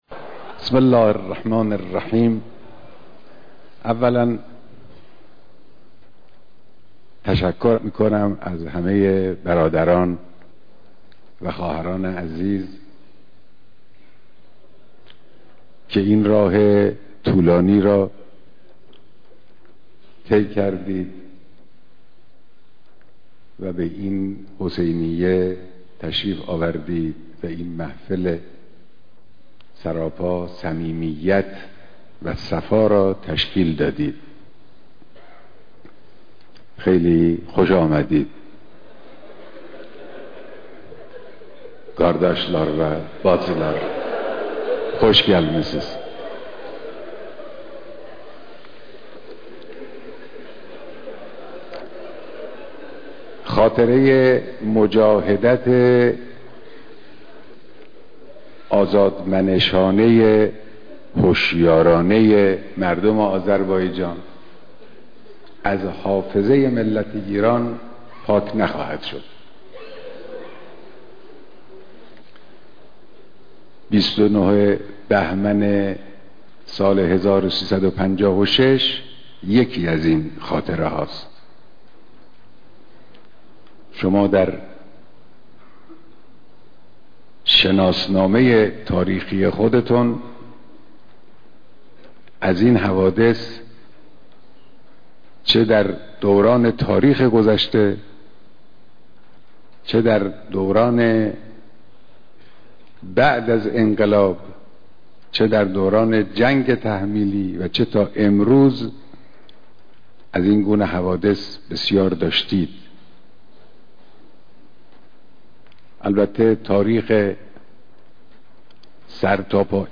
دیدار جمع زیادی از مردم آذربايجان شرقی در آستانه سالروز قیام 29 بهمن مردم تبریز
دیدار جمع کثیری از مردم تبریز